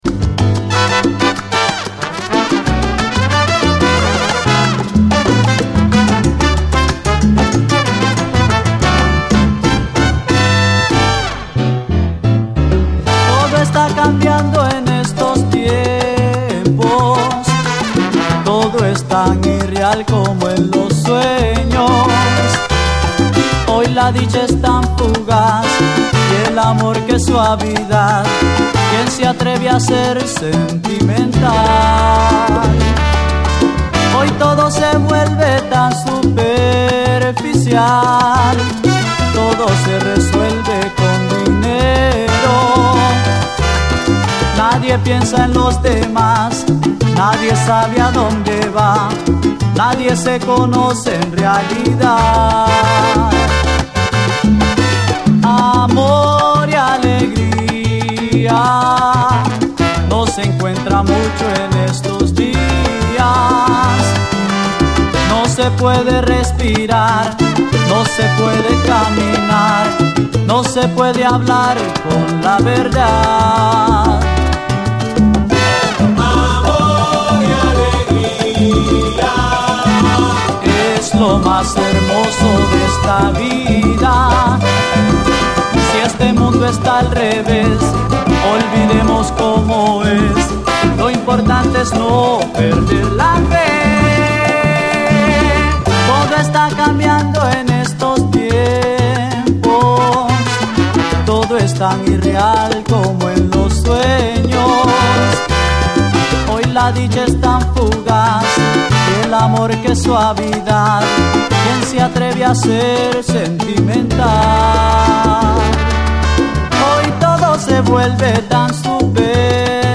中盤以降のストリングス〜ピアノ・フレーズがゾクゾクするほどスリリングでカッコいい！